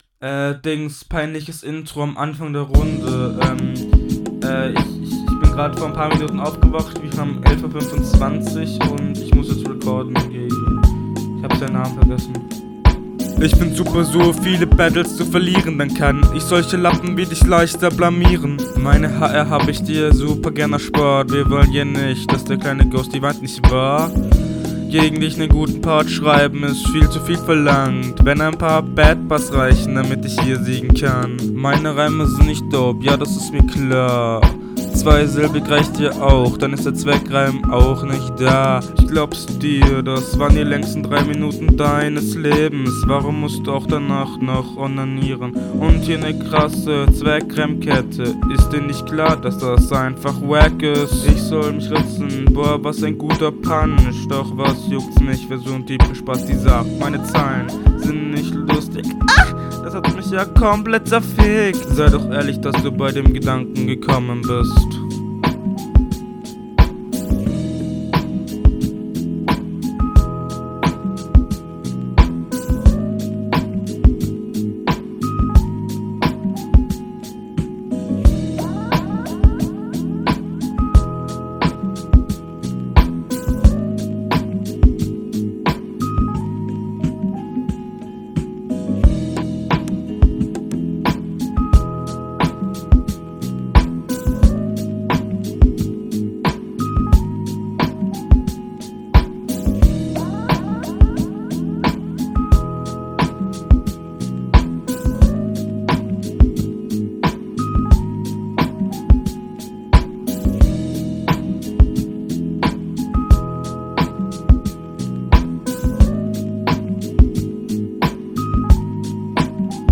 Flow: Flow super offbeat geflowt, leider ach sonst nichts gutes im flow.
Flow: ➨ Klingt direkt unroutinierter, als dein Gegner.